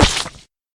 pumpkin_splat.ogg